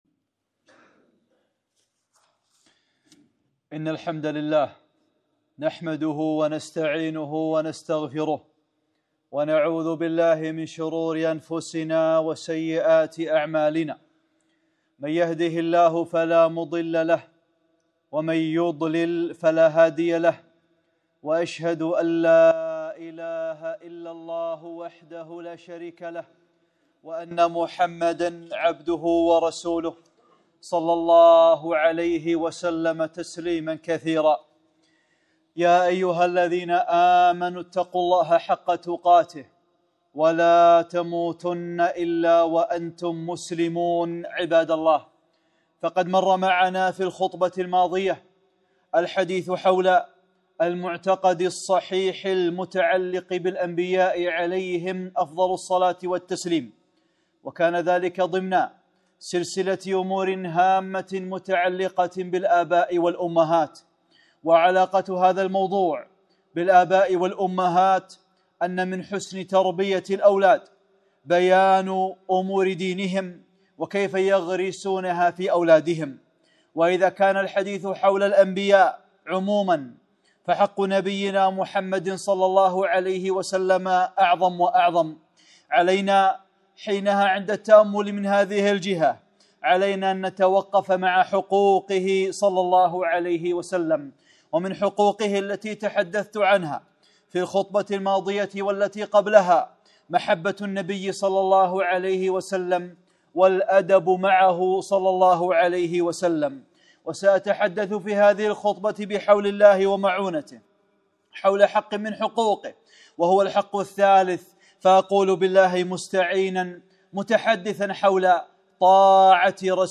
(35) خطبة - طاعة رسول الله ﷺ | أمور هامة متعلقة بالآباء والأمهات